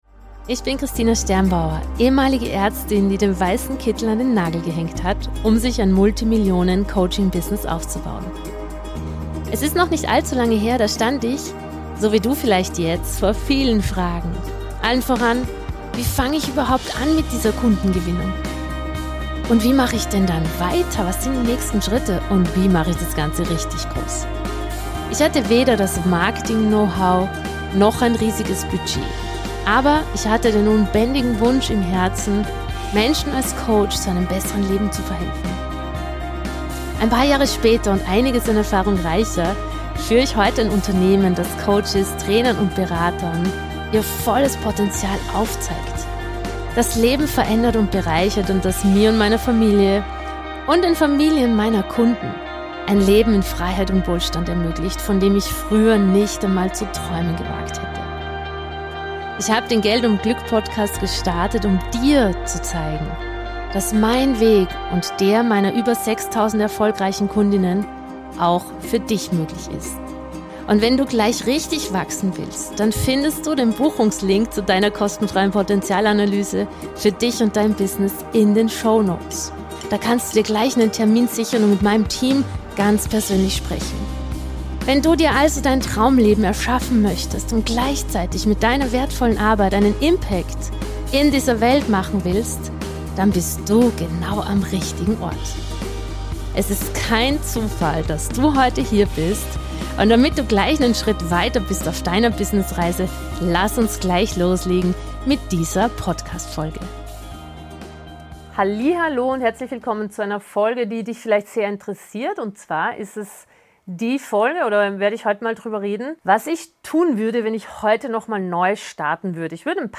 #099 Die Magie hinter den Worten – Interview